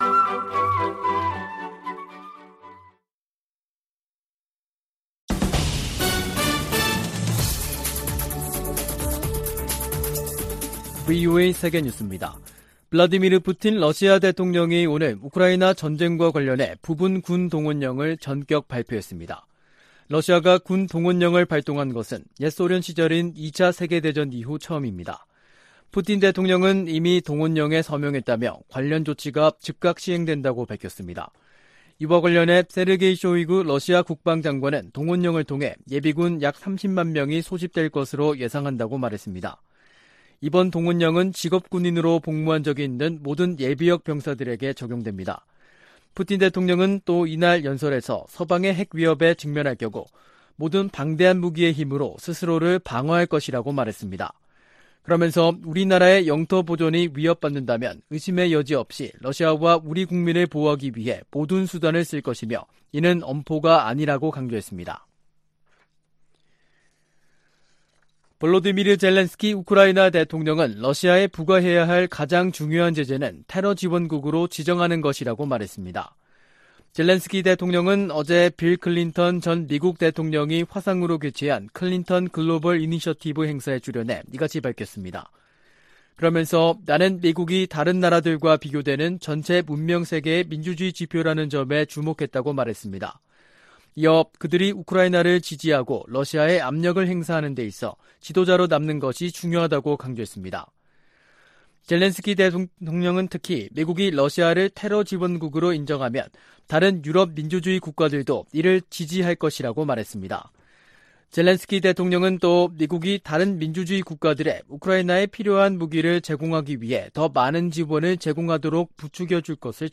VOA 한국어 간판 뉴스 프로그램 '뉴스 투데이', 2022년 9월 21일 2부 방송입니다. 조 바이든 미국 대통령이 유엔총회 연설에서 유엔 안보리 개혁의 필요성을 강조할 것이라고 백악관이 밝혔습니다. 윤석열 한국 대통령은 유엔총회 연설에서 자유를 지켜야 한다고 역설했습니다. 미국 정부가 북한인권특사 인선을 조만간 발표할 것으로 기대한다고 성 김 대북특별대표가 말했습니다.